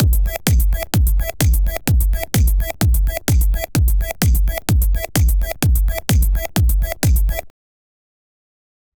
Stable Audio Open is an open-source model optimized for generating short audio samples, sound effects, and production elements using text prompts.